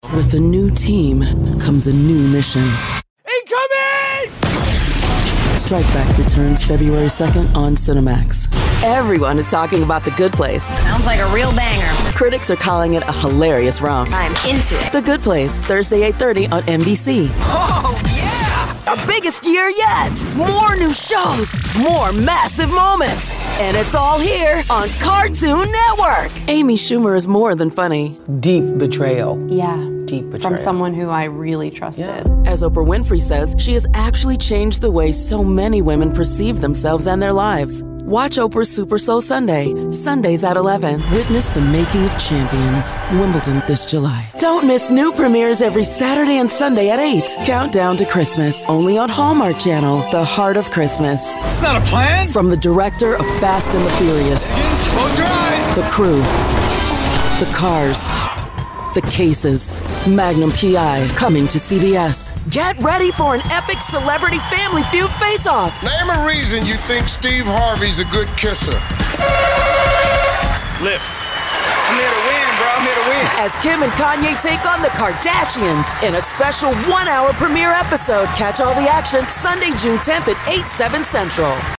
FEMALE VOICEOVER DEMOS